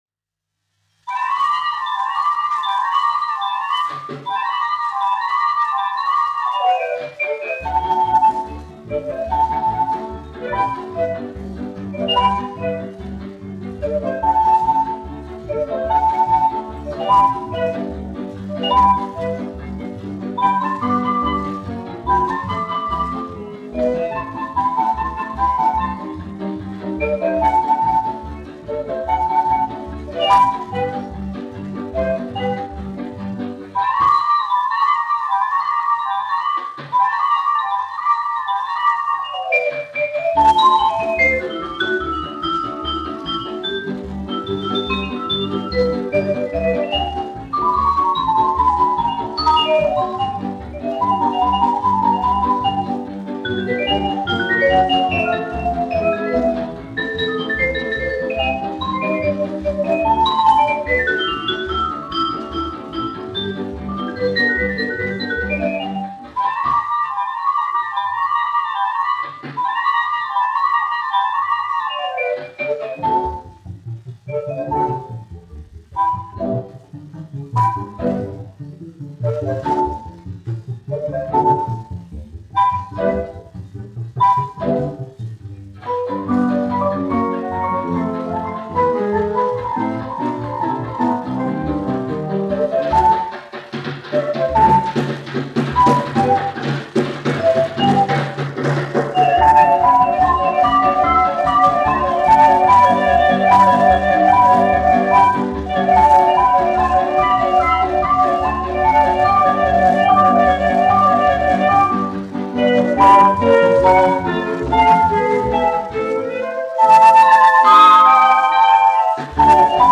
1 skpl. : analogs, 78 apgr/min, mono ; 25 cm
Džezs
Populārā instrumentālā mūzika
Skaņuplate